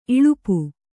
♪ iḷupu